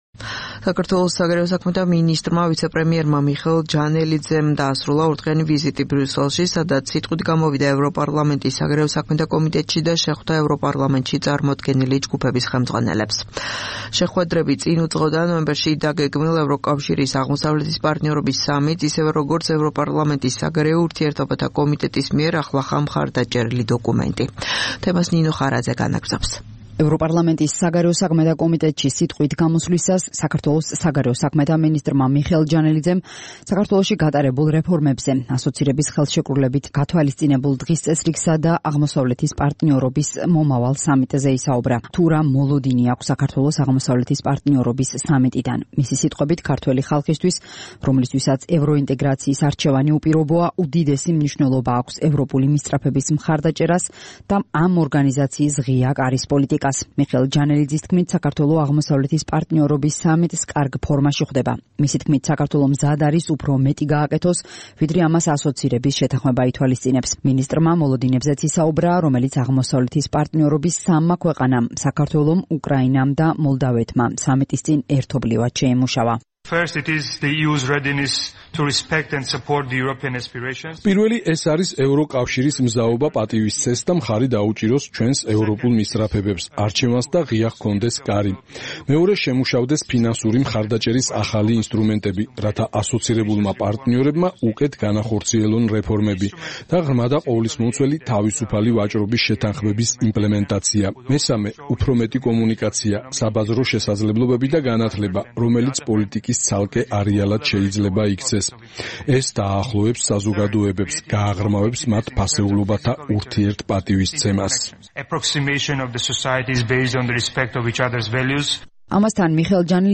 საგარეო საქმეთა მინისტრი მიხეილ ჯანელიძე, ბრიუსელში ოფიციალური ვიზიტის ფარგლებში, სიტყვით გამოვიდა ევროპარლამენტის საგარეო საქმეთა კომიტეტის სხდომაზე.